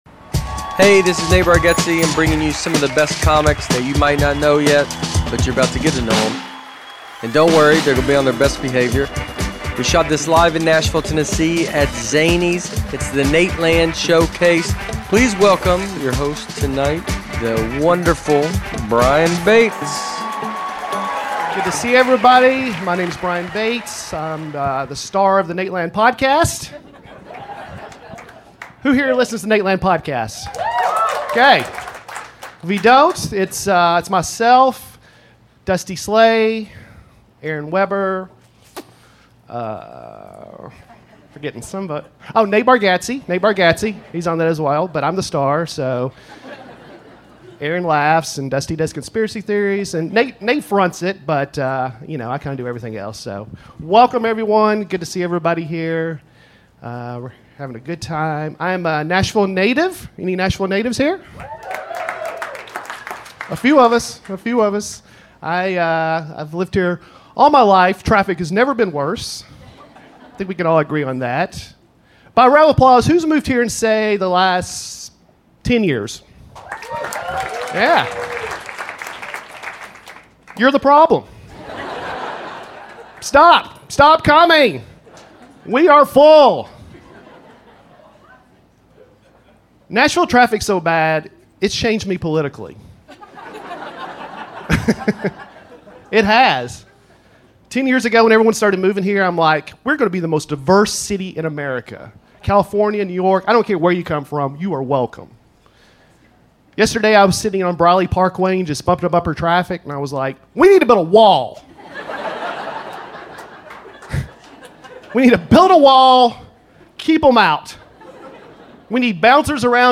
The Showcase features several of the best comics that you might not know yet, but you're about to get to to know 'em. Hosted by the members of The Nateland Podcast and directed by Nate Bargatze himself, The Showcase was recorded live at Zanies Comedy Club in Nashville, Tennessee. Standup comedy at its best.